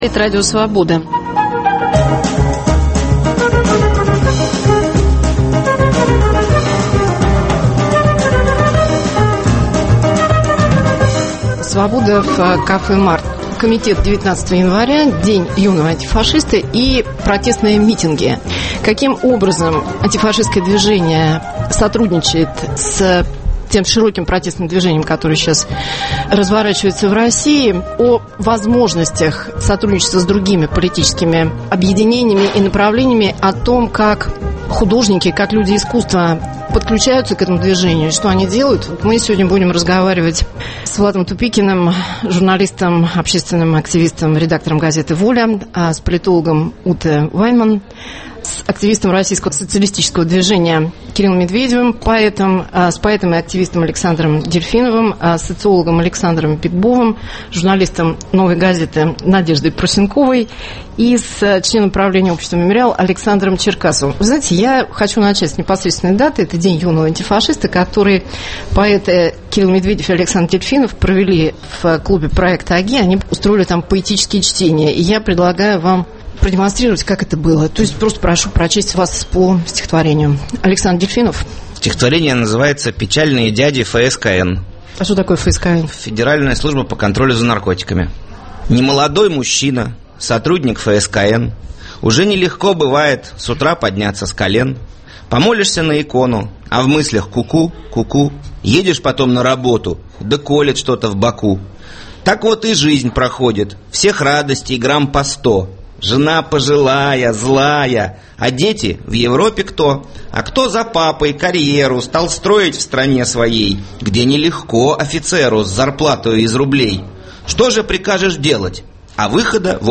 Свобода в кафе Март.